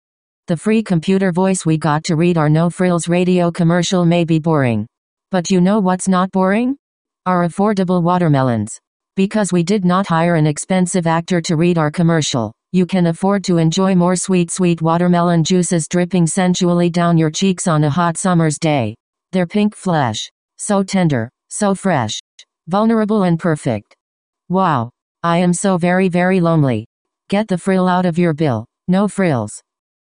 The cost of actors, studio time and post-production for radio ads ends up on your grocery bill, according to this No Frills’ campaign. Because the grocery retailer is all about saving customers money, it skipped most of those steps and used a cold robotic voice (think Siri’s less famous cousin) to narrate the scripts.